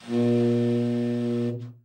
Index of /90_sSampleCDs/Giga Samples Collection/Sax/TEN SAX SOFT